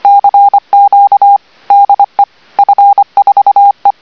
LA TELEGRAPHIE, LE MORSE (CW)
- 25 mots/mn